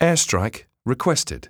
yourequestedairstrike.wav